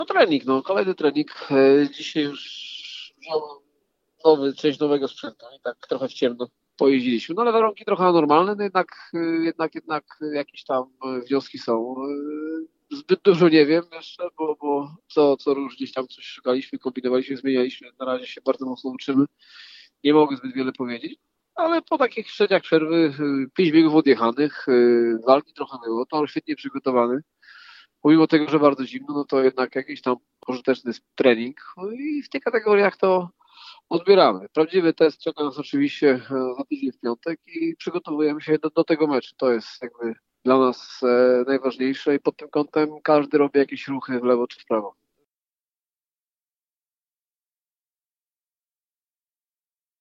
– Potrzebuję jeszcze trochę jazdy – mówił nam dziś Piotr Protasiewicz – kapitan Falubazu Zielona Góra.